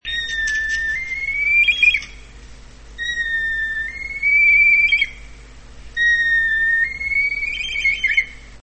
Kania ruda - Milvus milvus
głosy